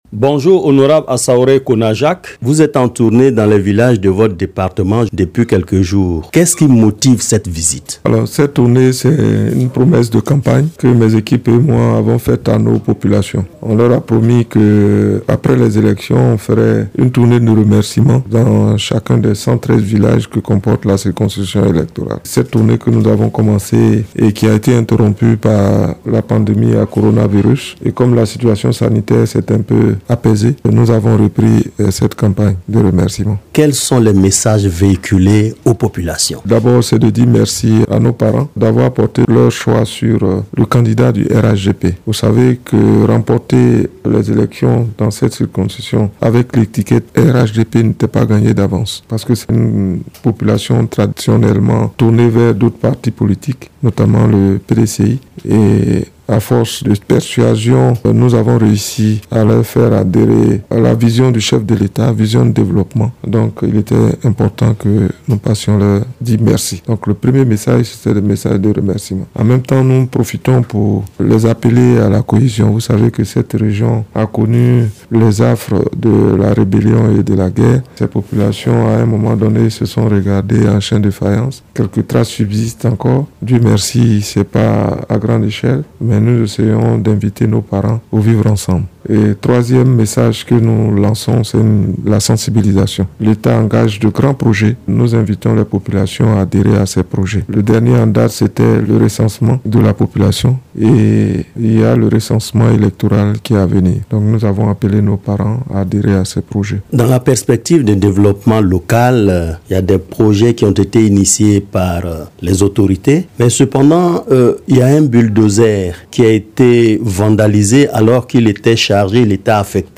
Dans le fauteuil de l’invité de la Rédaction de ce jour, le député de Diabo-Languibonou dans le département de Bouaké. Le parlementaire fait le point de la tournée qu’il entreprend depuis quelques jours dans les villages de sa circonscription pour parler de cohésion sociale, de civisme, de développement et de lutte contre la violence.
invite-de-la-redaction-depute-de-diabo-languibonou.mp3